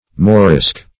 morisk - definition of morisk - synonyms, pronunciation, spelling from Free Dictionary Search Result for " morisk" : The Collaborative International Dictionary of English v.0.48: Morisk \Mo"risk\, n. Same as Morisco .
morisk.mp3